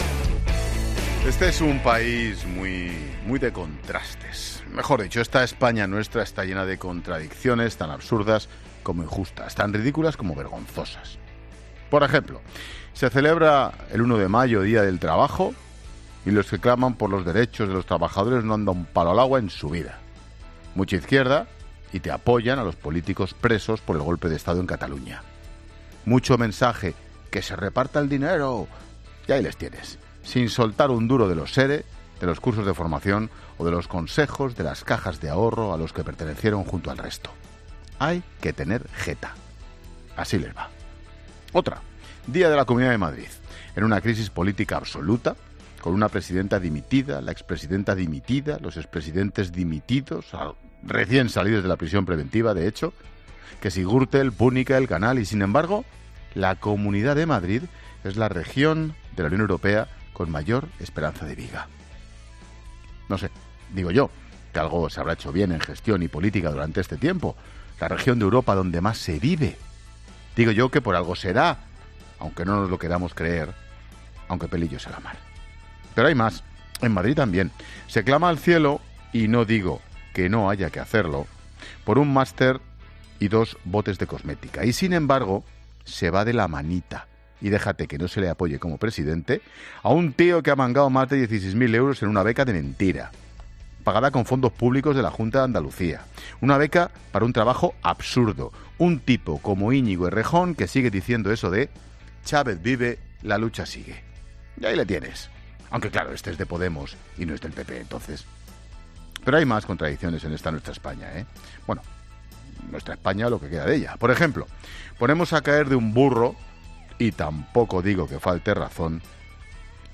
Monólogo de Expósito
El comentario de Ángel Expósito en el Día de la Comunidad de Madrid, tras la dimisión de Cifuentes.